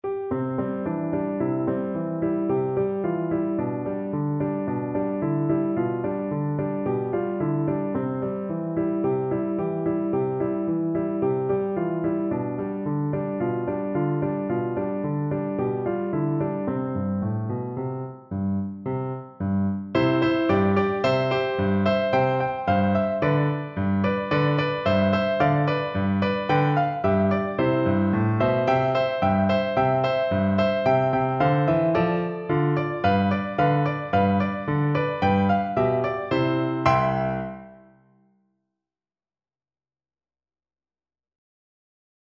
Free Sheet music for Piano
No parts available for this pieces as it is for solo piano.
C major (Sounding Pitch) (View more C major Music for Piano )
Playfully =c.110
4/4 (View more 4/4 Music)
Classical (View more Classical Piano Music)
i_have_a_dreydl_PNO.mp3